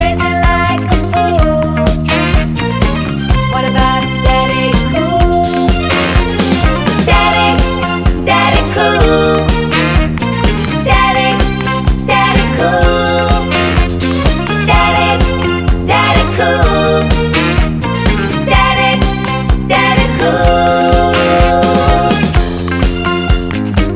Female Solo Disco 3:24 Buy £1.50